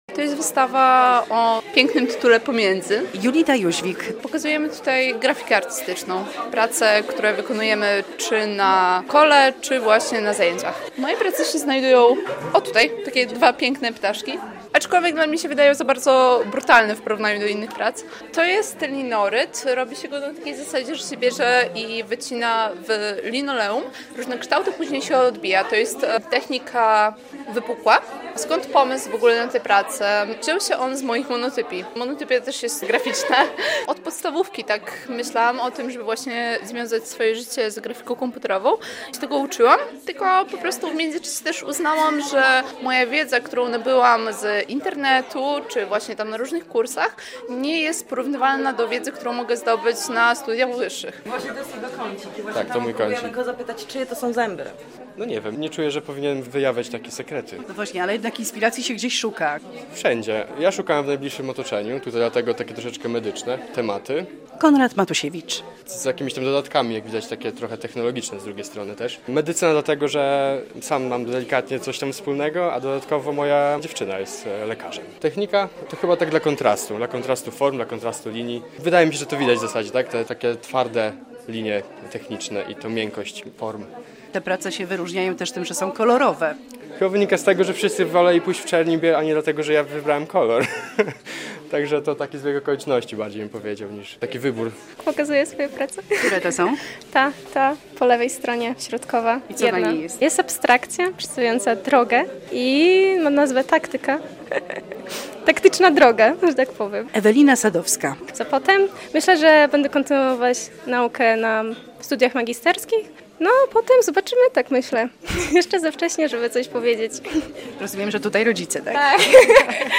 Wystawę można oglądać w Galerii Marchand. Młodzi pasjonaci grafiki artystycznej prezentują swoje prace wykonane w technikach linorytu i suchorytu.